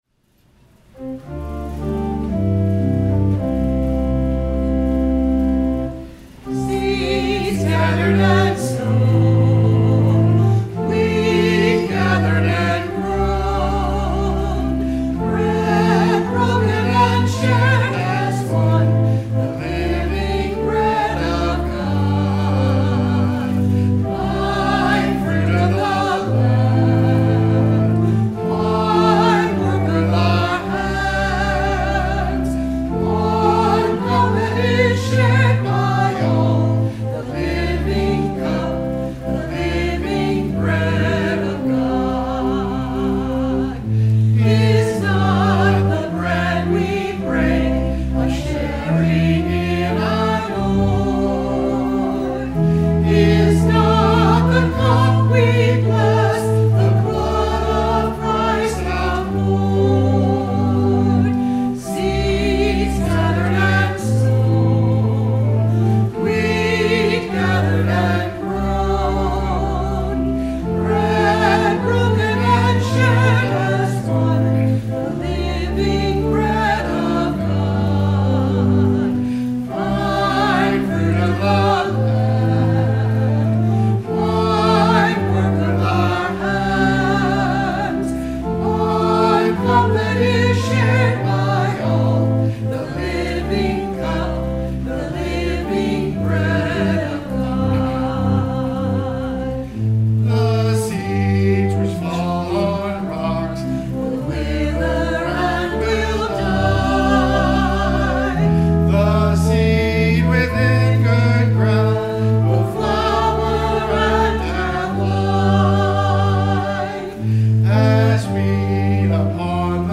Performed by the Algiers UMC Choir
Anthem